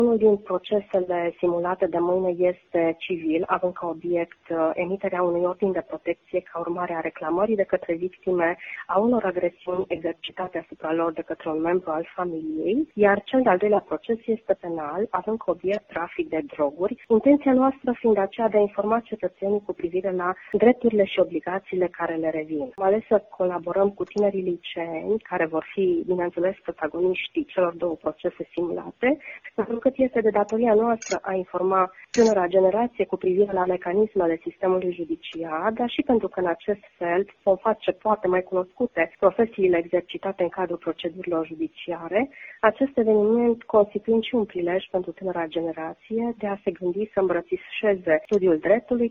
Președintele delegat al Tribunalului Mureș, Veronica Szasz.